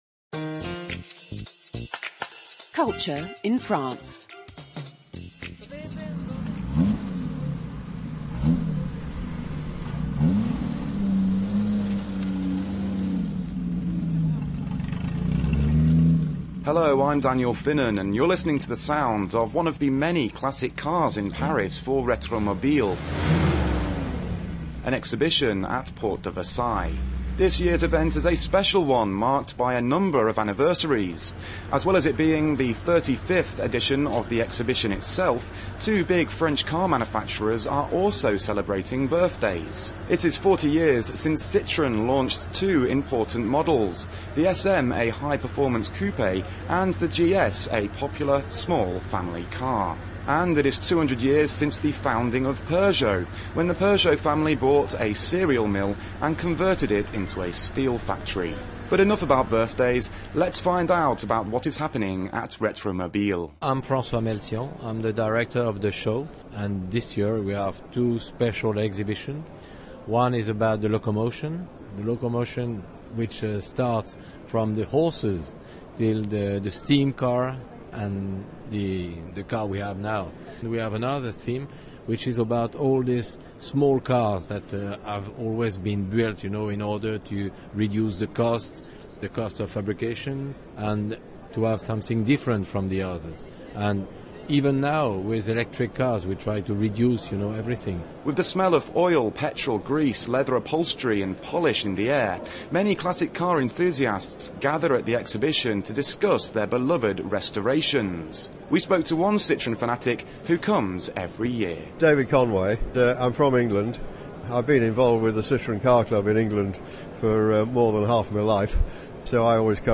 Radio Feature: Culture in France